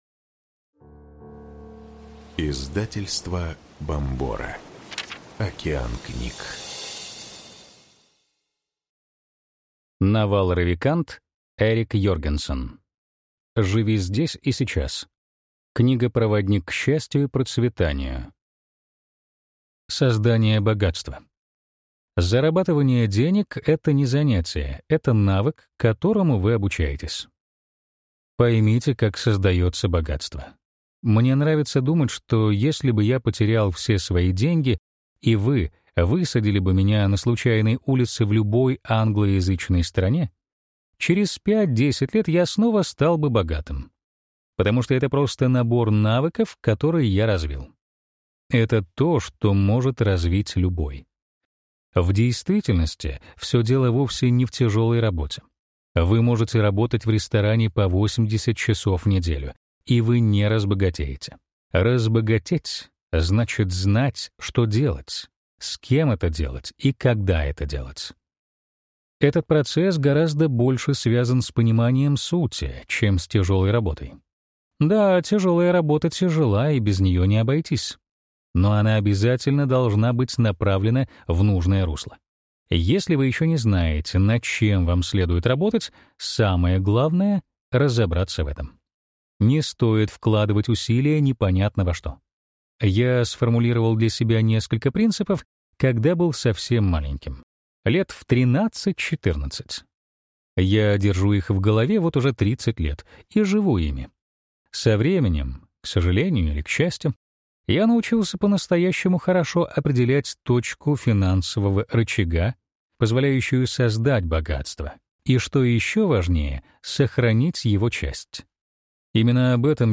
Аудиокнига ЖИВИ здесь и сейчас. Книга-проводник к счастью и процветанию | Библиотека аудиокниг